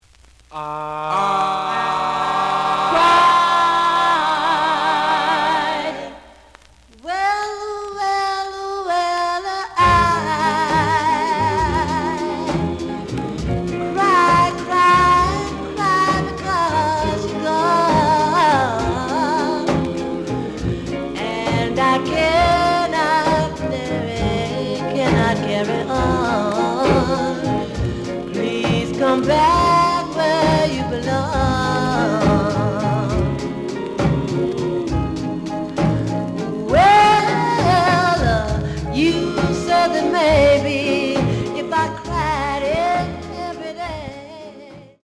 The audio sample is recorded from the actual item.
●Genre: Rhythm And Blues / Rock 'n' Roll
Some clikc noise on first half of B side due to a bubble.)